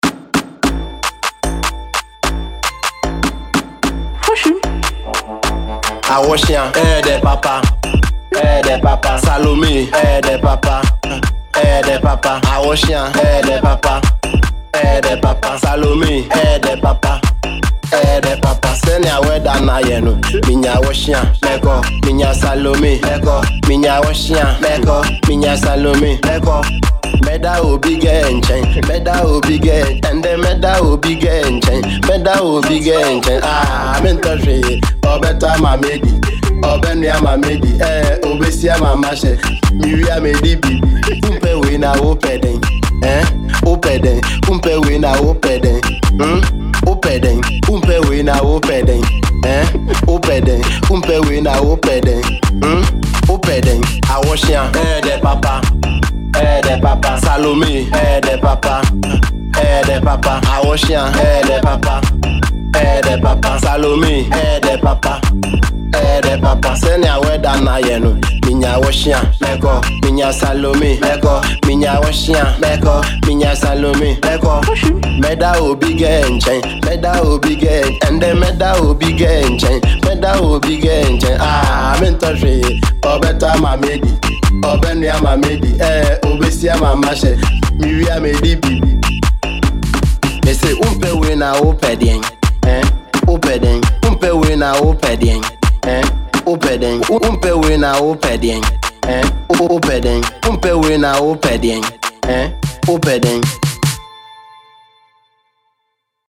Enjoy this danceable tune.